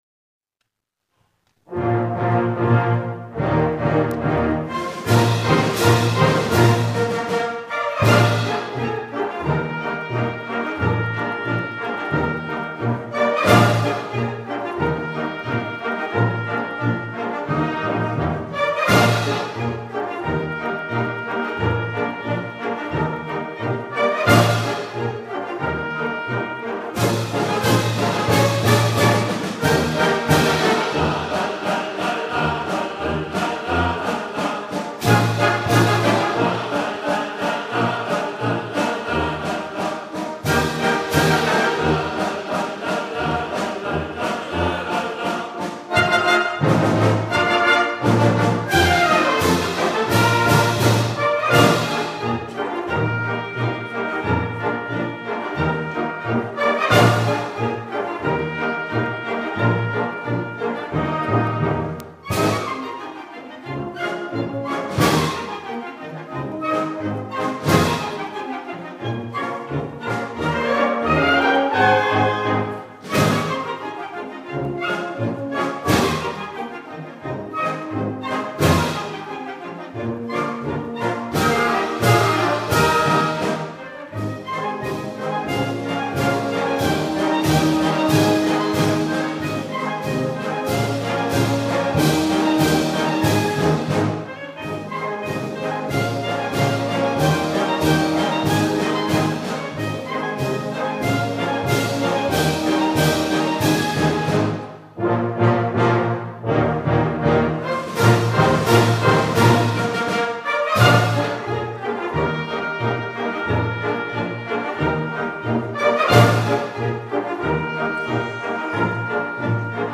Gattung: Polka francaise
Besetzung: Blasorchester